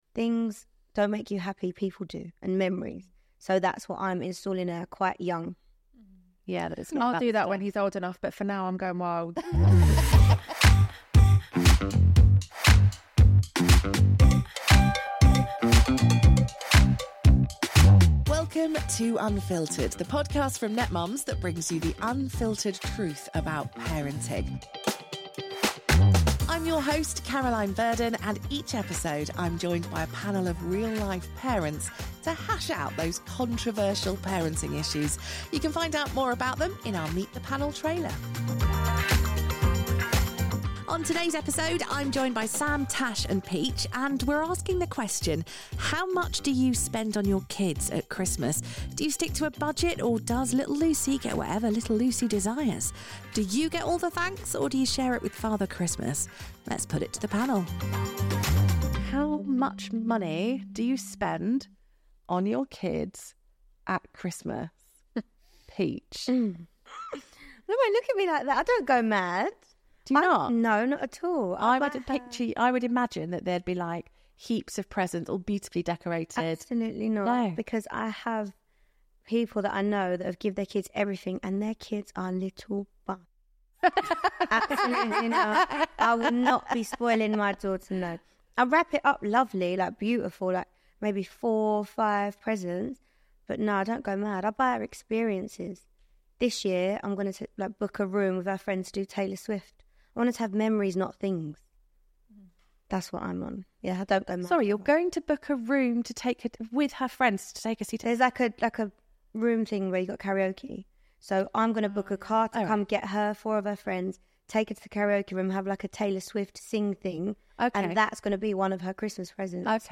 Tune in for a lively chat full of laughs, debates, and a dash of holiday spirit.